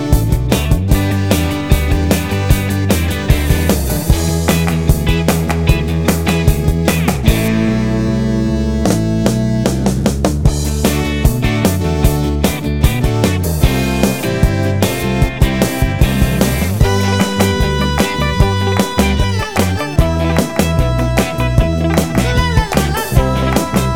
No Piano End Cut Down Pop (1970s) 3:47 Buy £1.50